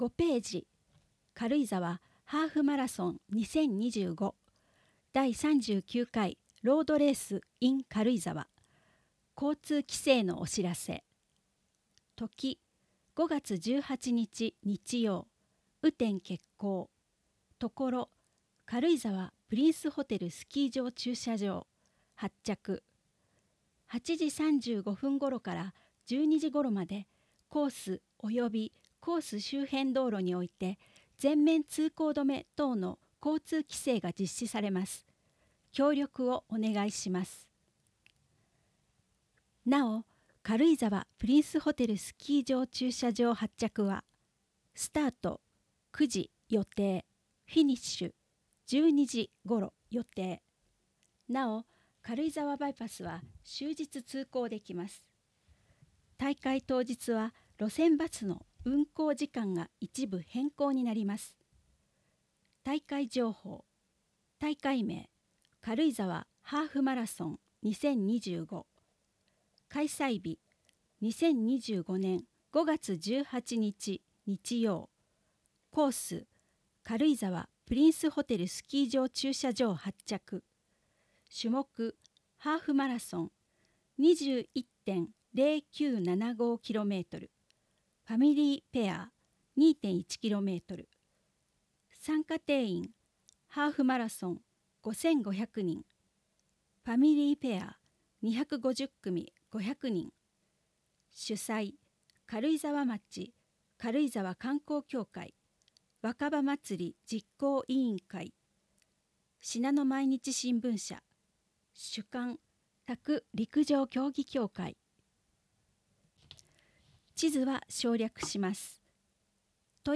音声データ　軽井沢図書館朗読ボランティア「オオルリ」による朗読